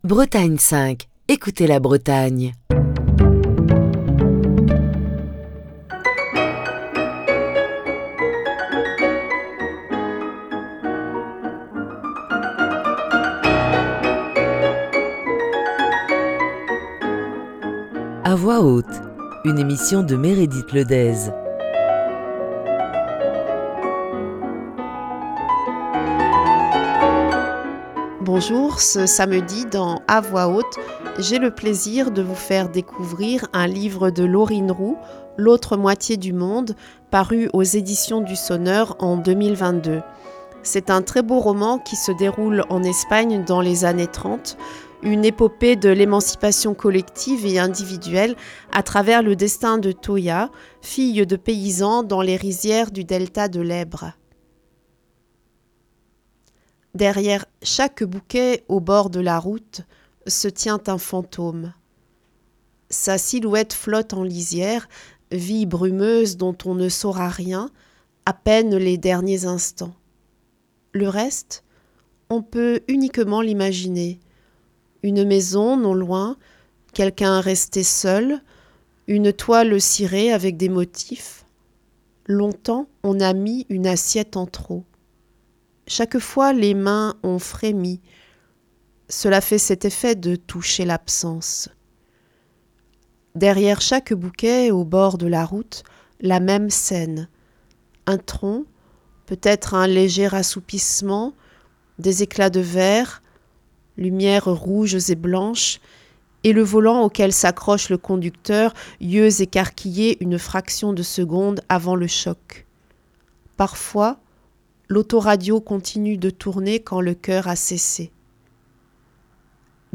avec la lecture de ce roman de Laurine Roux